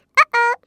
Mouse Squirrel Sound Effects » Hey Mouse Squirrel Cartoon
描述：A Mouse/Squirrel/Cartoon sound saying "Hey".
标签： cartoony scream mouse voice hi shout cartoon vocal hey chipmunk funny squirrel squeaky cute
声道立体声